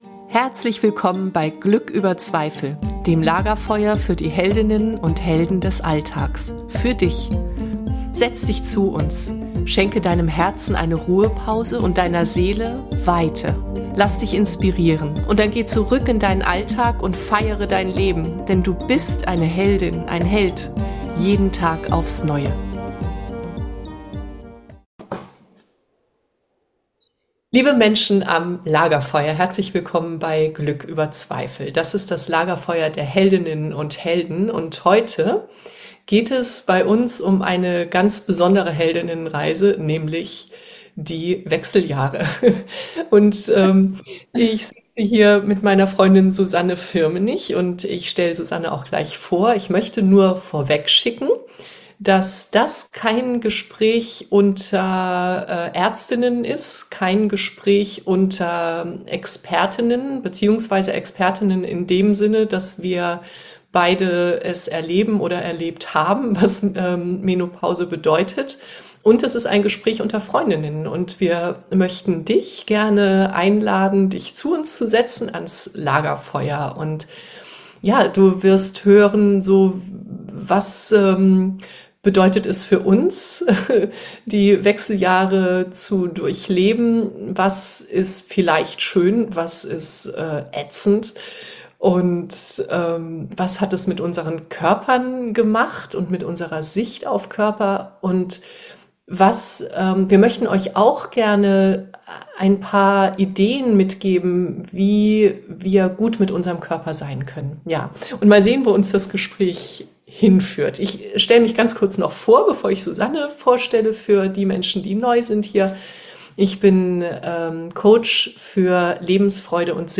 Dich erwartet ein authentisches Gespräch unter Freundinnen und viele wertvolle Gedanken über einen gesunden Umgang mit unserem Körper und mit Schlaflosigkeit.